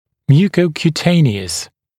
[ˌmjuːkəukju’teɪnɪəs][ˌмйу:коукйу’тэйниэс]кожно-слизистый